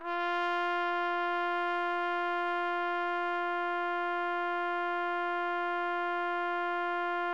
TRUMPET   12.wav